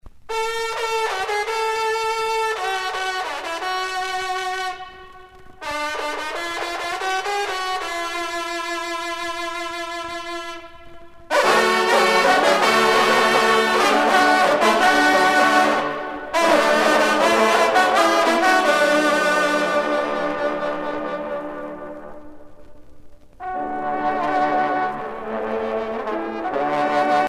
trompe - fanfare
circonstance : vénerie